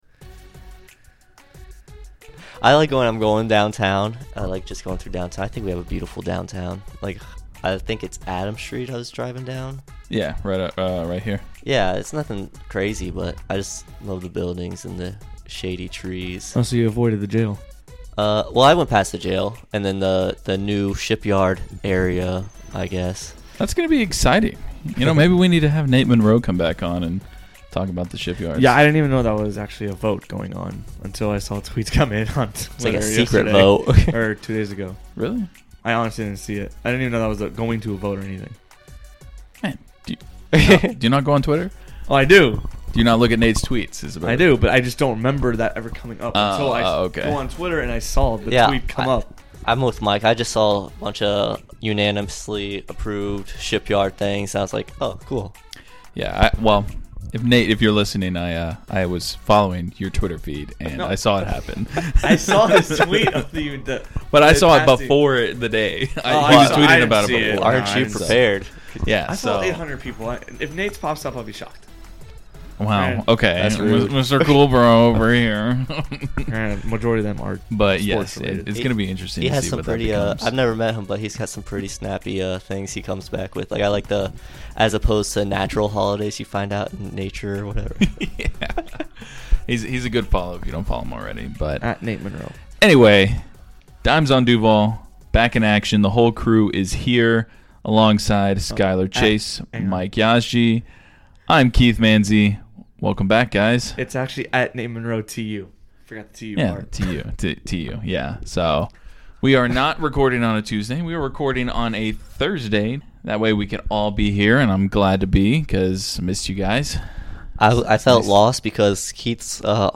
The Coastal Indoor Golf Studio was full for this episode! No remote members of the pod meant more fun all around, and much deeper takes as well.